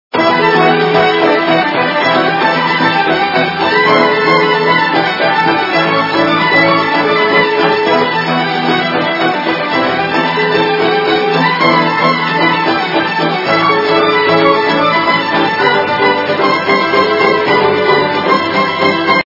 народные
качество понижено и присутствуют гудки